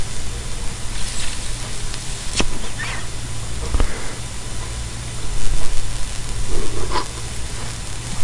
烟雾
描述：从香烟中击出并扔掉烟雾。
Tag: 烟雾 卷烟